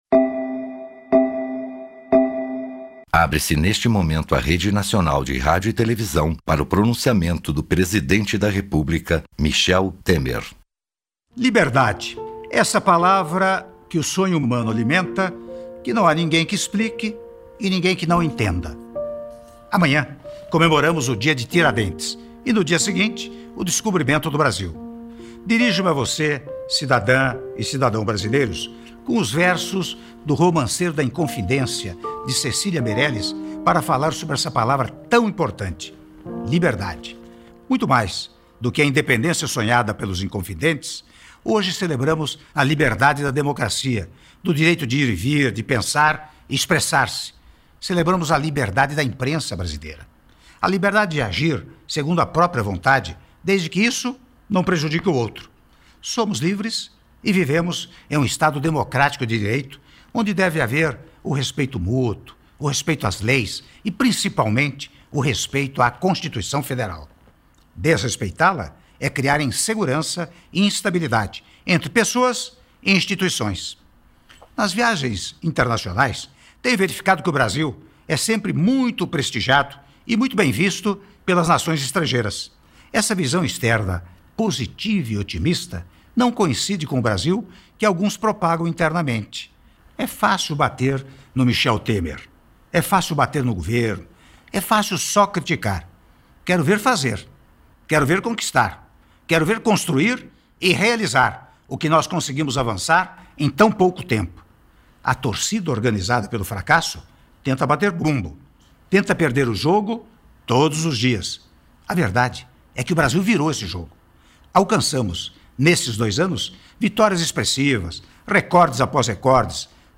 Áudio do pronunciamento do Presidente da República, Michel Temer, em cadeia de rádio e televisão (05min)